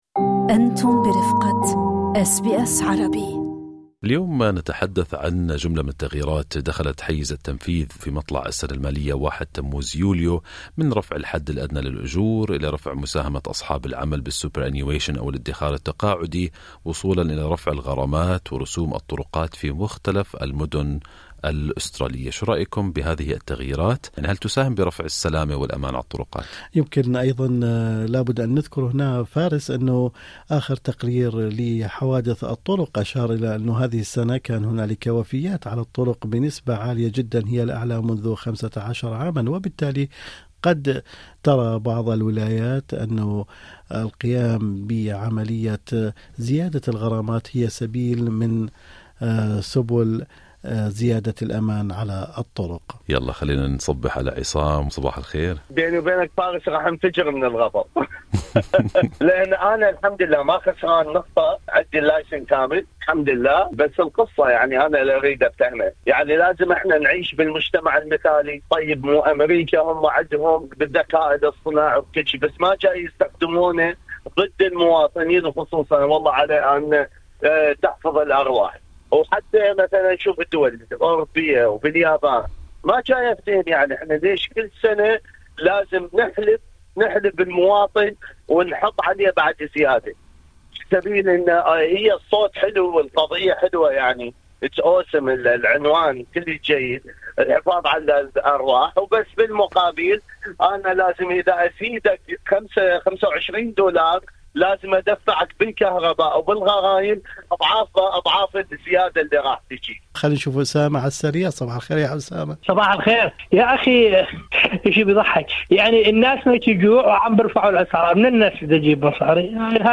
برنامج Good Morning Australia سأل أفرداً من الجالية العربية عن آرائهم في هذه التغييرات.